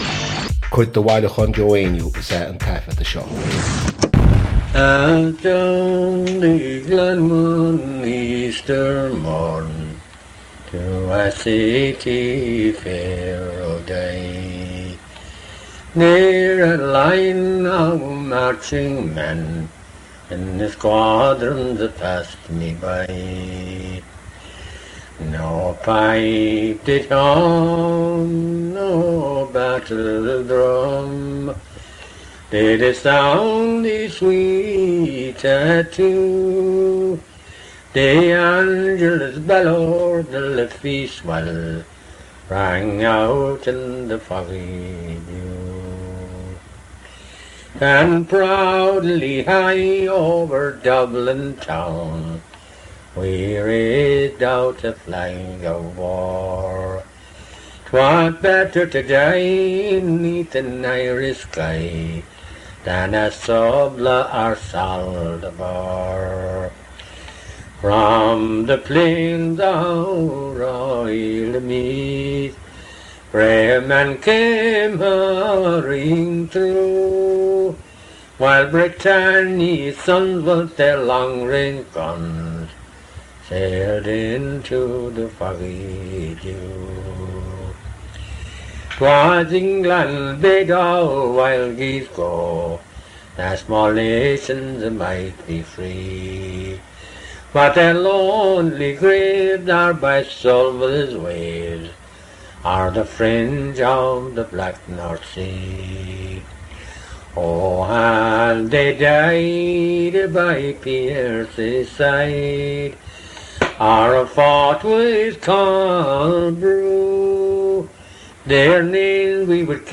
• Catagóir (Category): song.
• Ainm an té a thug (Name of Informant): Joe Heaney.
• Ocáid an taifeadta (Recording Occasion): private.